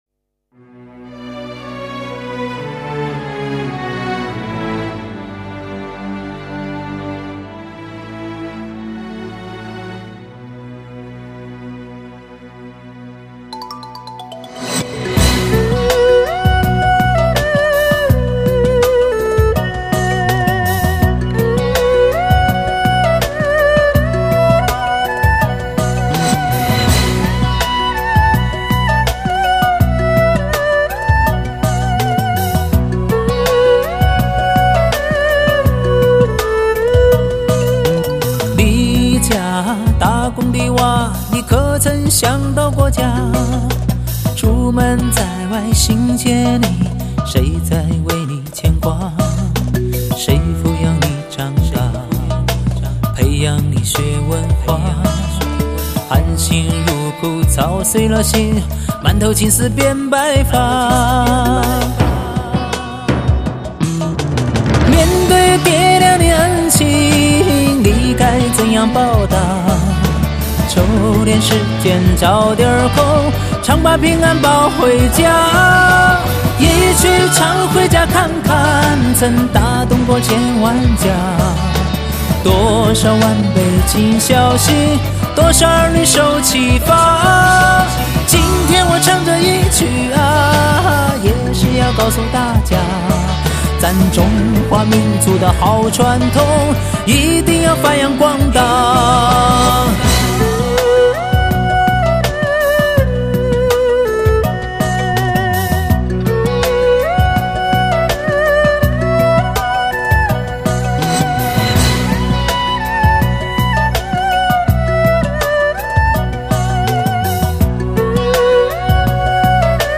类型: 流行经典